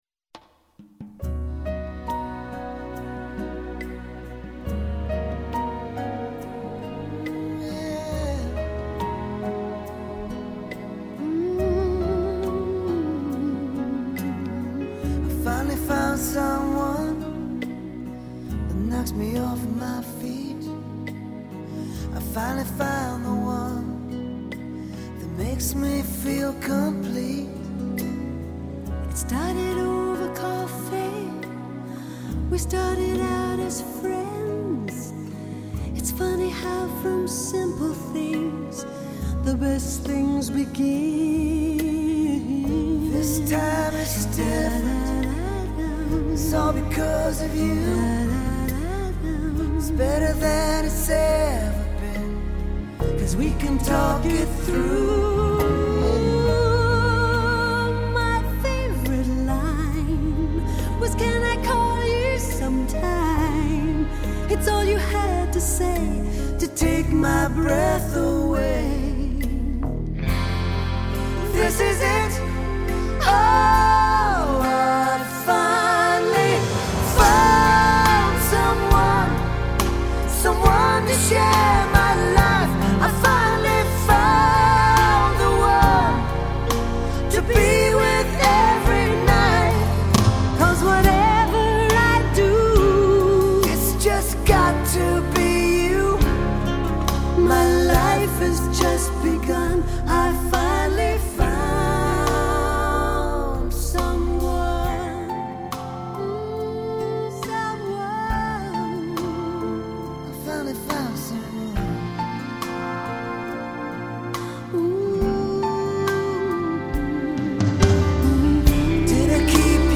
Genre: Rock/Pop